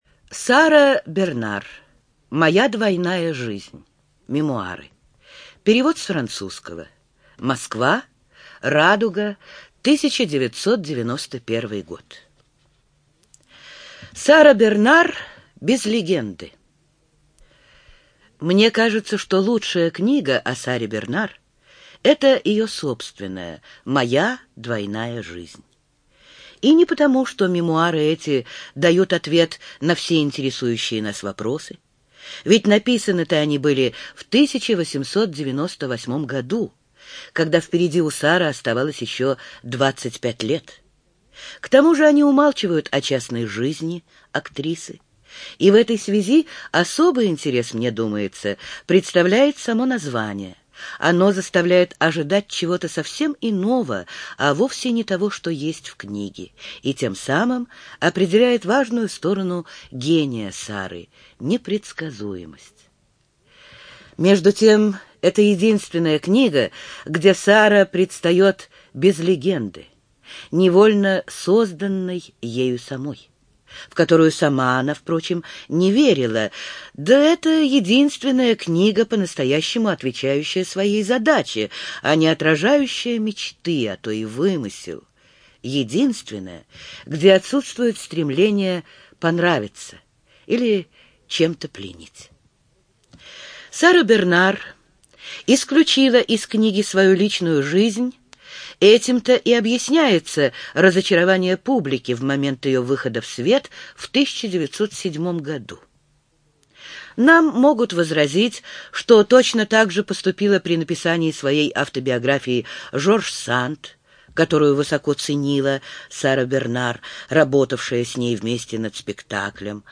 ЖанрНаучно-популярная литература, Биографии и мемуары
Студия звукозаписиЛогосвос